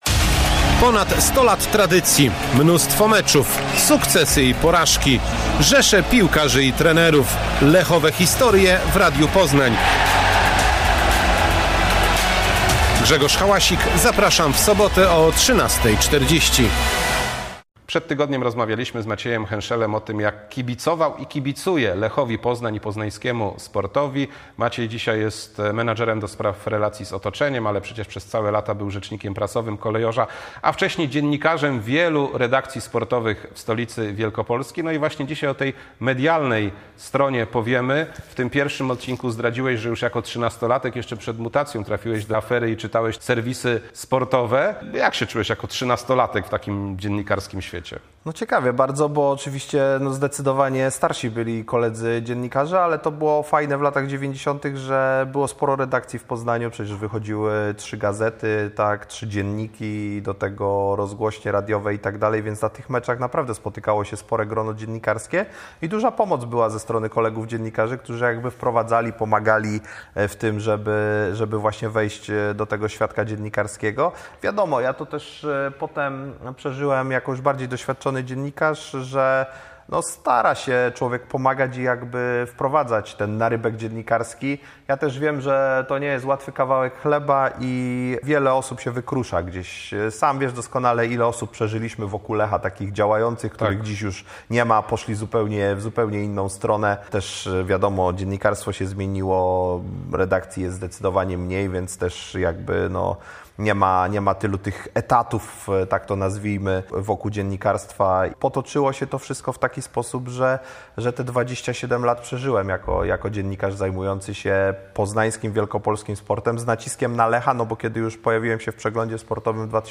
W czternastym odcinku "Lechowych historii" druga część rozmowy z